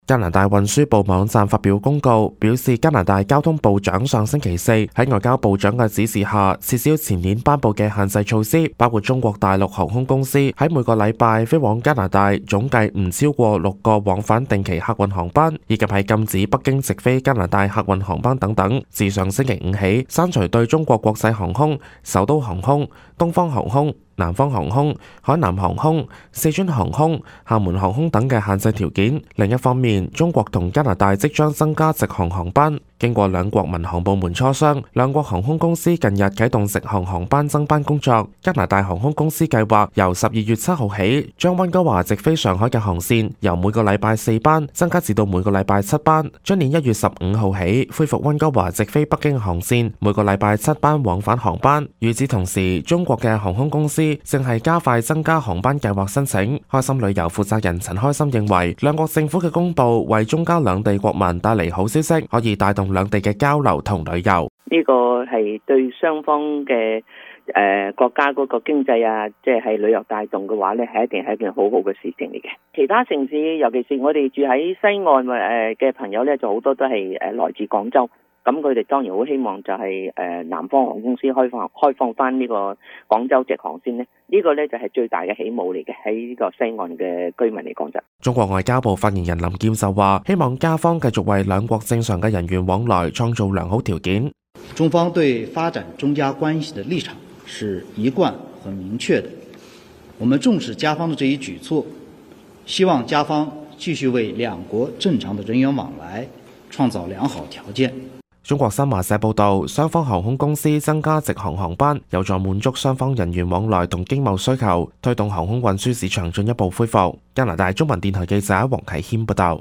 news_clip_21092.mp3